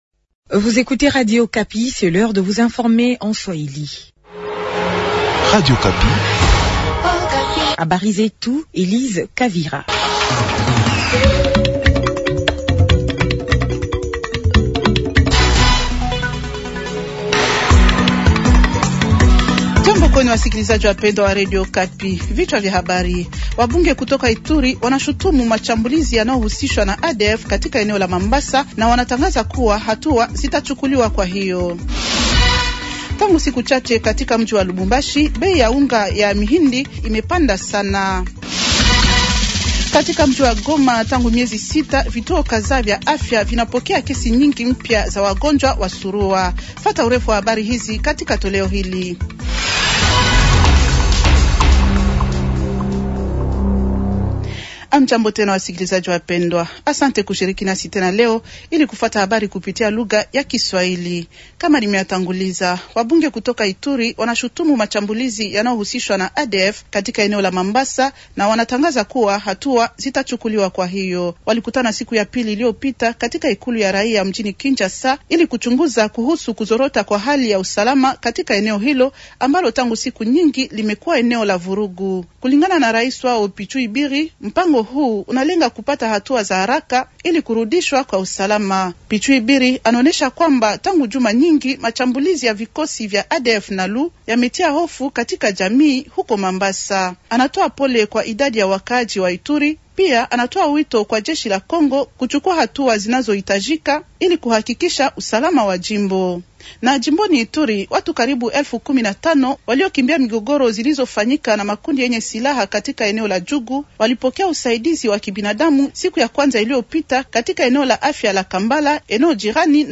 Journal Swahili de jeudi matin 190326